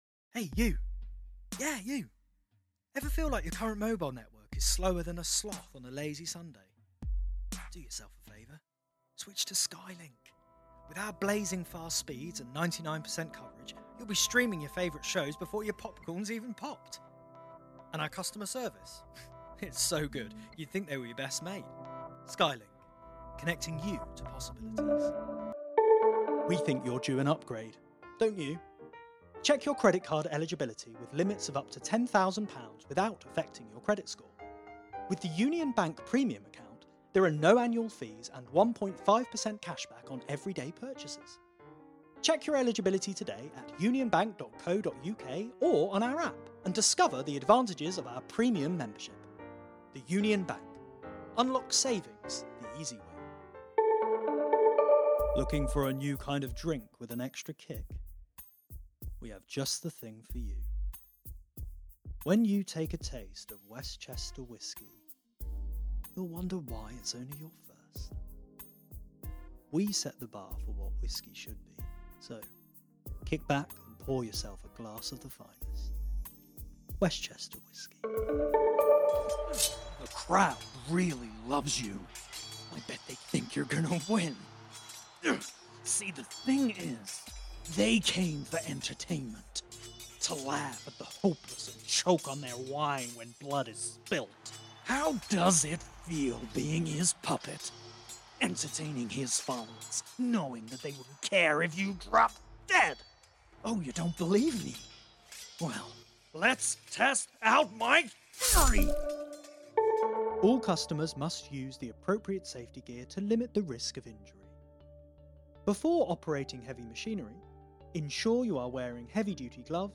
VOICE REEL